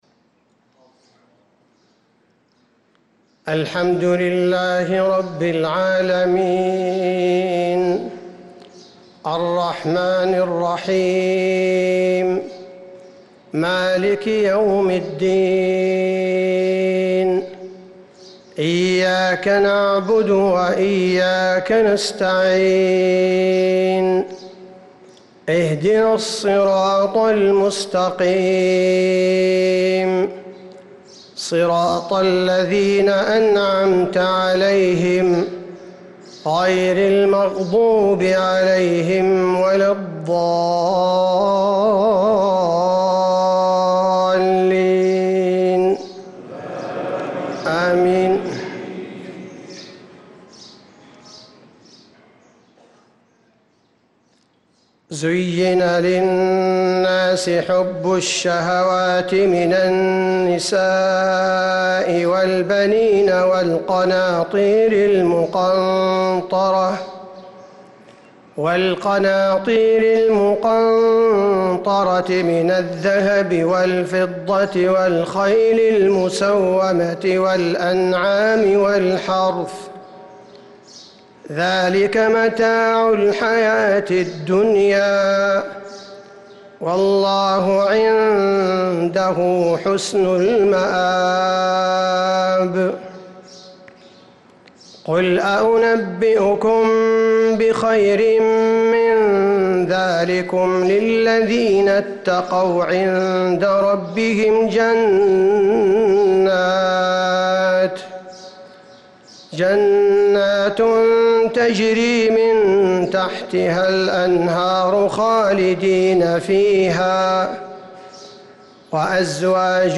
صلاة الفجر للقارئ عبدالباري الثبيتي 28 رمضان 1445 هـ
تِلَاوَات الْحَرَمَيْن .